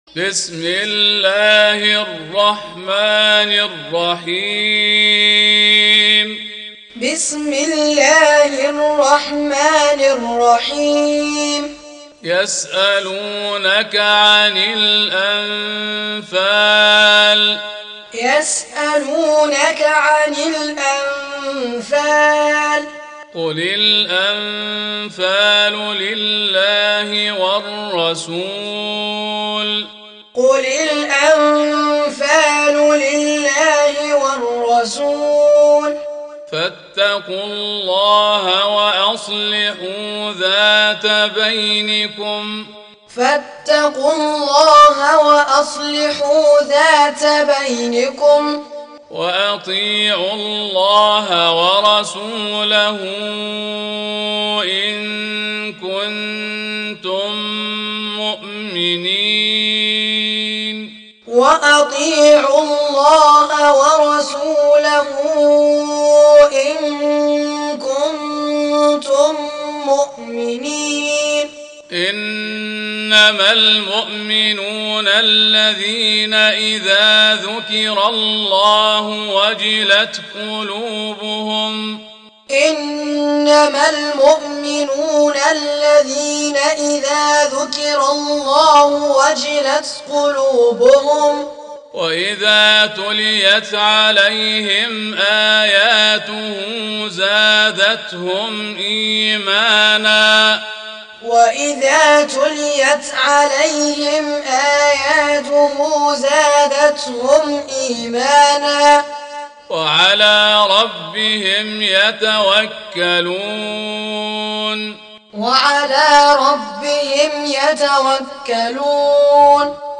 Recitation Teaching Qur'an One to One